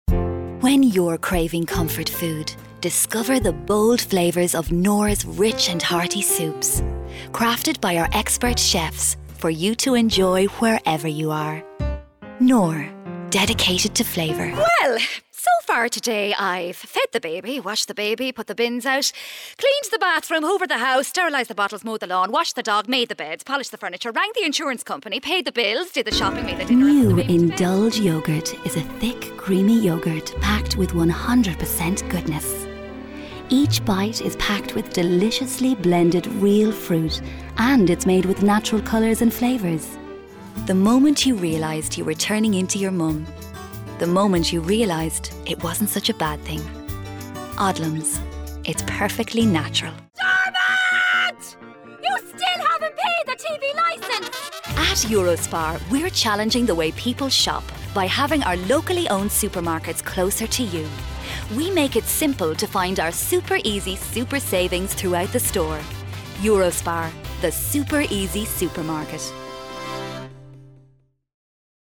Female Voice over Artist